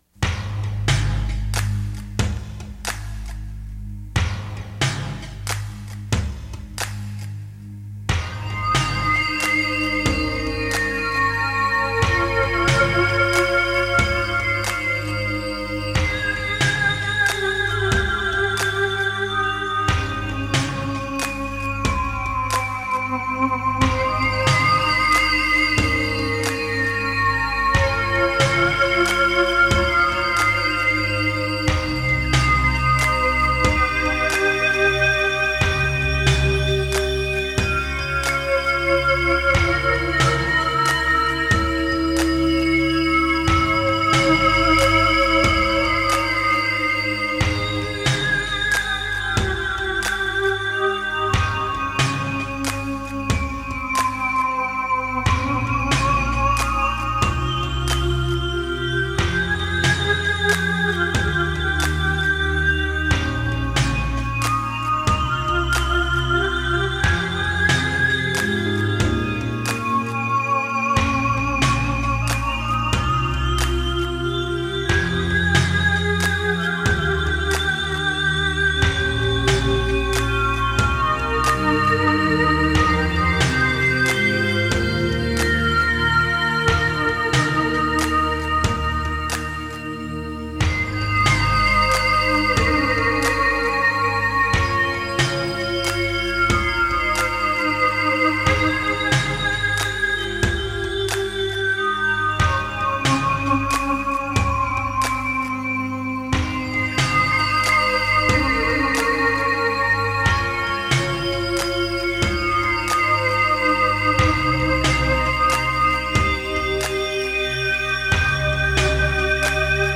A modestly ambitious (at least, for this amateur musician and would-be recording studio engineer) 4-track home recording of some pieces quite on my mind at the time. The master tape had deteriorated somewhat over the years prior to its eventual transfer to digital media in the mid-2000s.